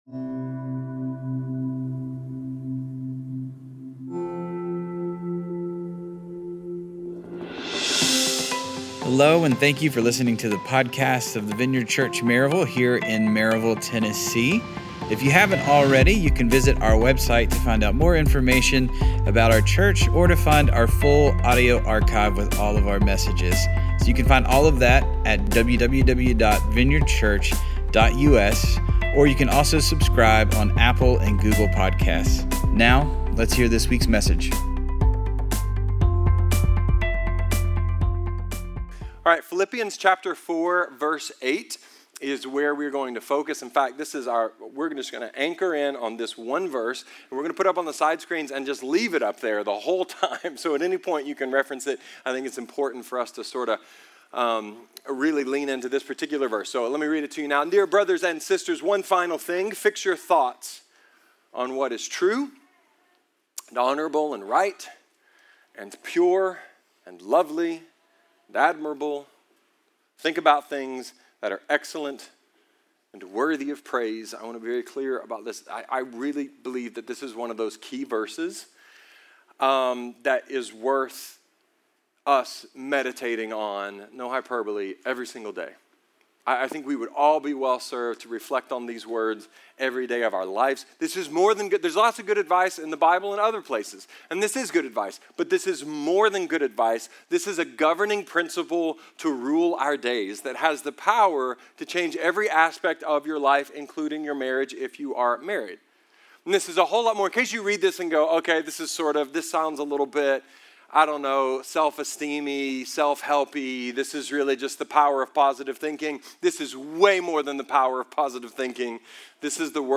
A sermon about how biblical principles (not tips & tricks), can keep the spark alive.